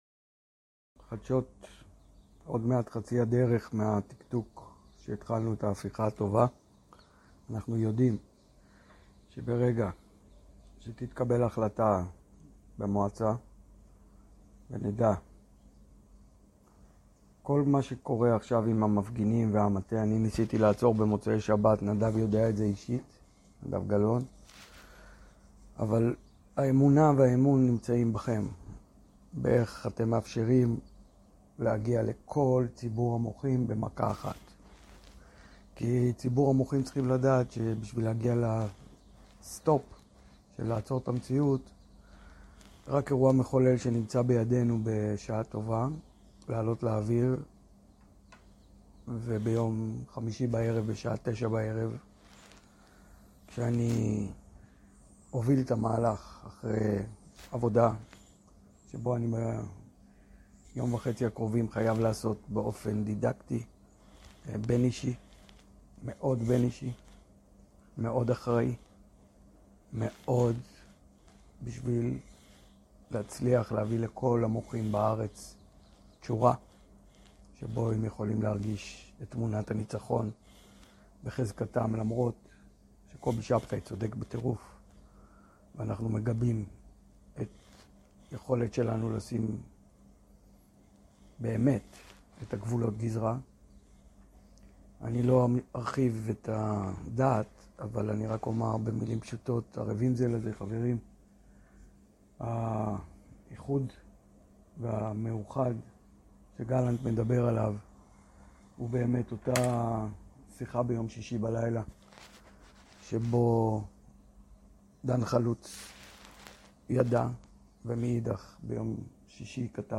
הרצאה מבואות היום.